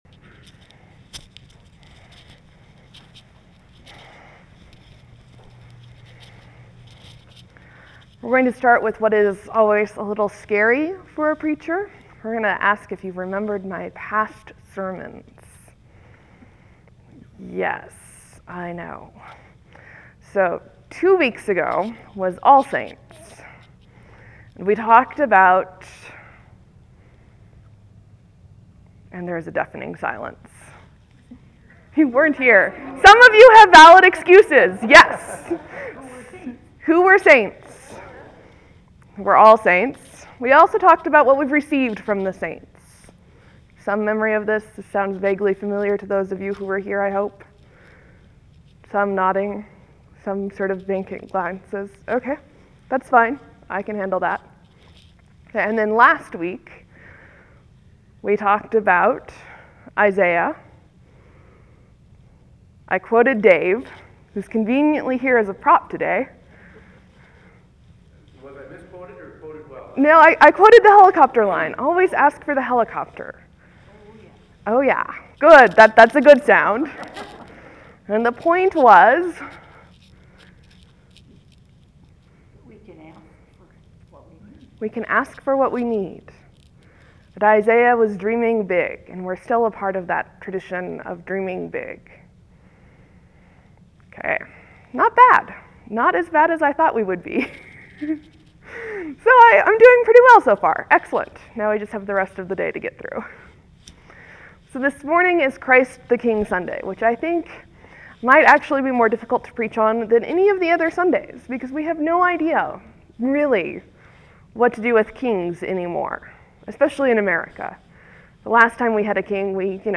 (There may be a few seconds pause before the sermon starts.)